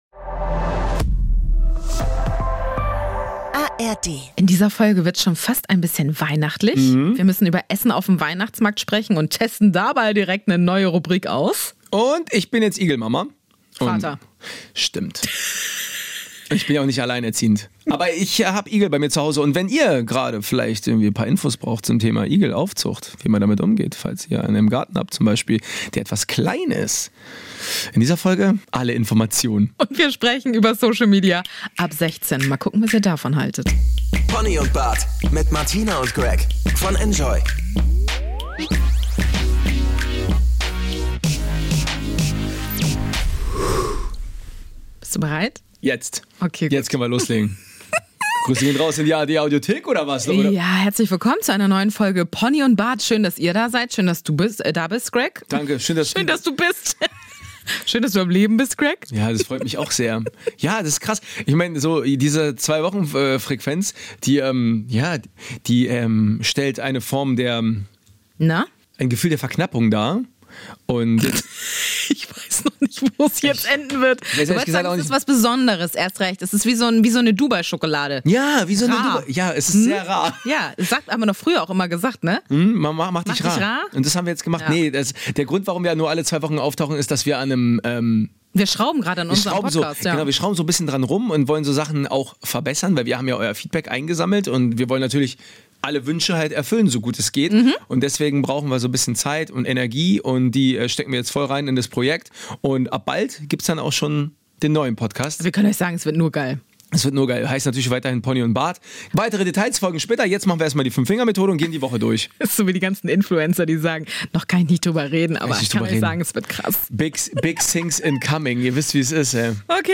3 Frauen, 3 Religionen, 1 Thema